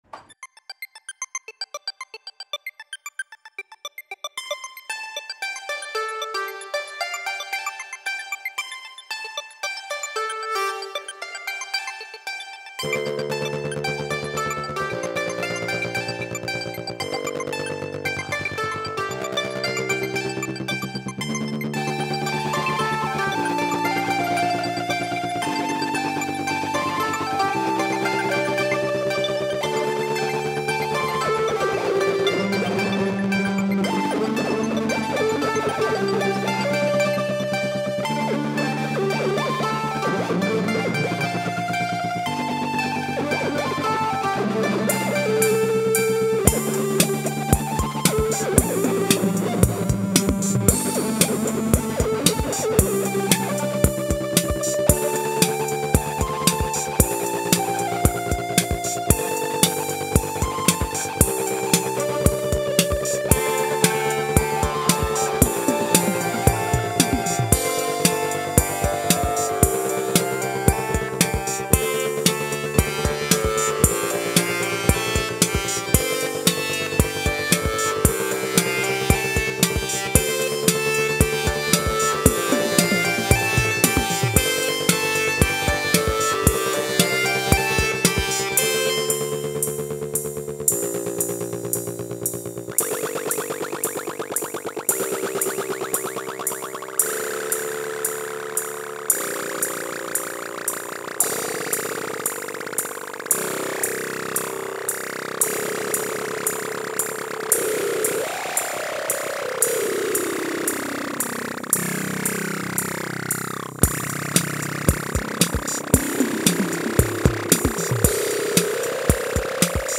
I wired up a patchbay in my home studio and ran some of my gear through my hand-built effects pedals.
• Bus 1 - Moon Phaser - back into the mixer and there’s a bus going to the phaser
• Behringer K2 - Gristleiser - big muff
• Behringer Wasp - Tremolo and RAT
• Make Noise 0-coast - octaver and annihilator fuzz
• Behringer Deepmind 6 is playing the plucky randomised arps
Beats are coming from the Behringer RD-8 and a cymbal hit from the Alesis SR-16.
Added a bit of reverb on the XR12.
Analog-FX-Jam.mp3